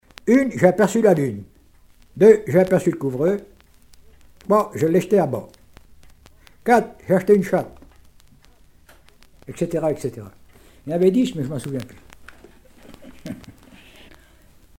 enfantine : comptine
Genre brève